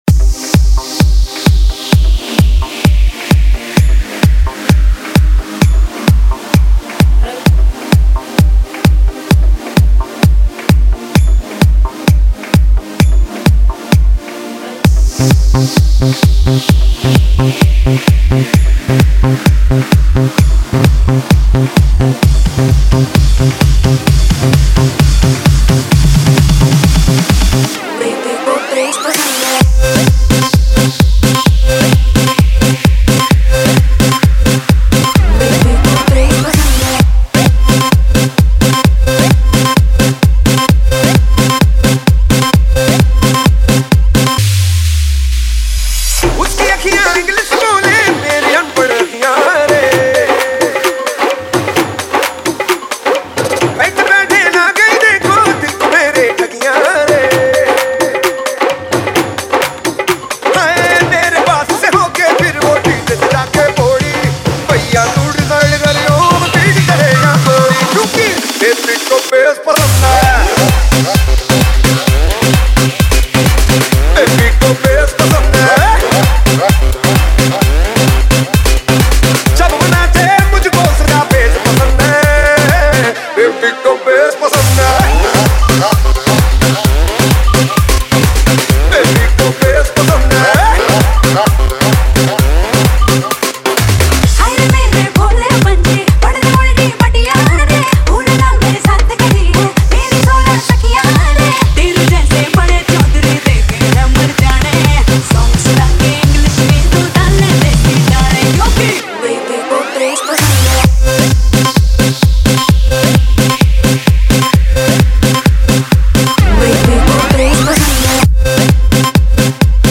DJ Remix Mp3 Songs > Single Mixes